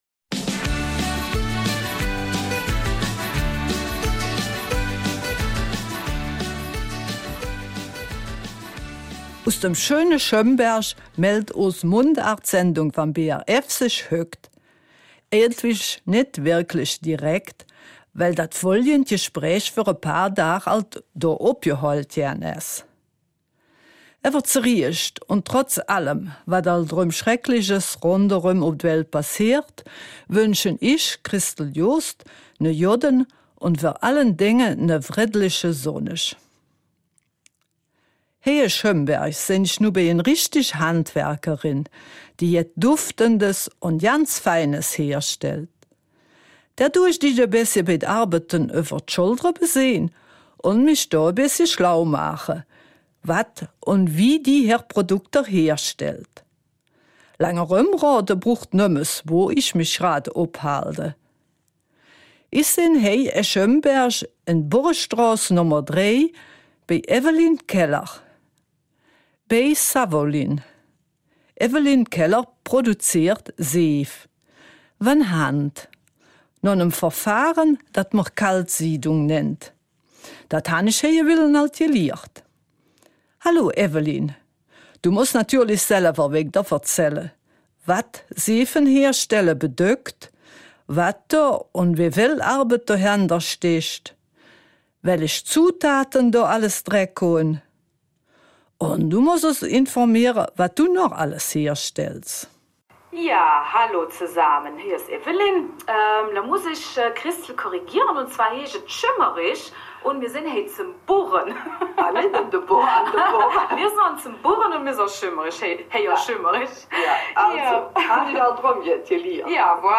Eifeler Mundart: Savonlyne